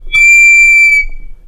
Звуки микрофона
Звук скрипящего микрофона (раздражающий)